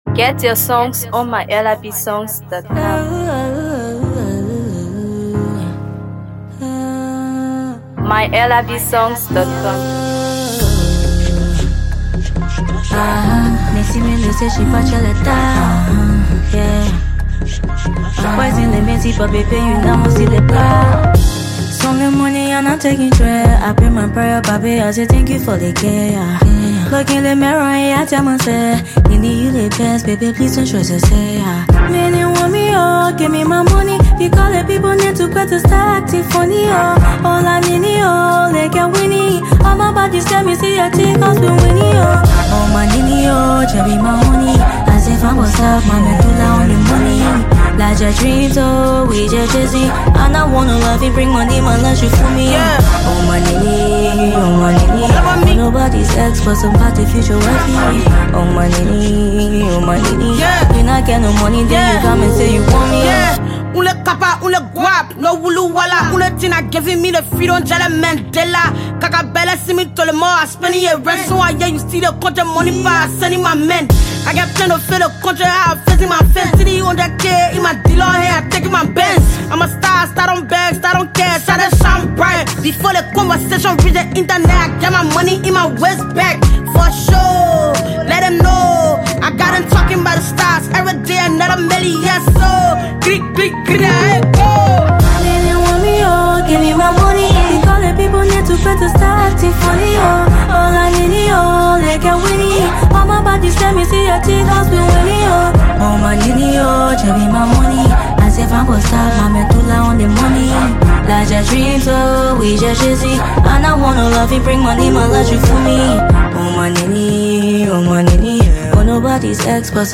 Afro PopMusic
soulful vocals